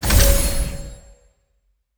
sfx_reward 01.wav